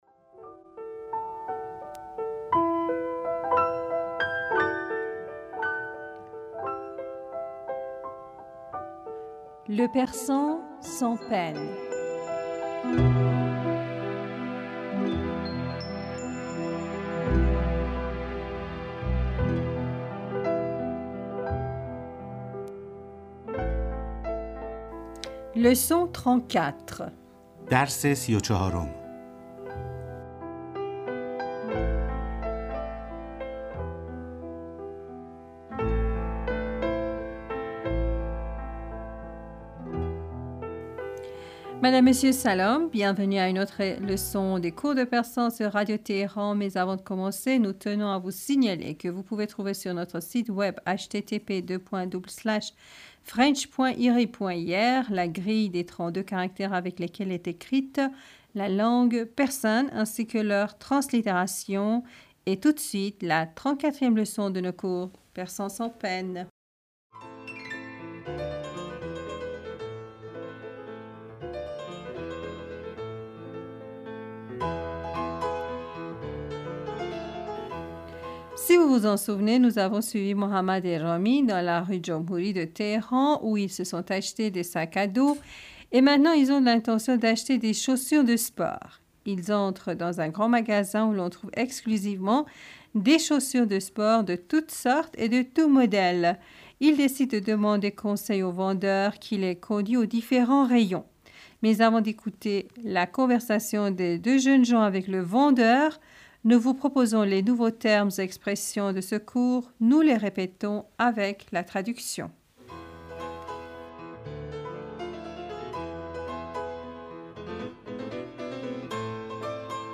Bienvenus à une autre leçon des cours de persan sur Radio Téhéran.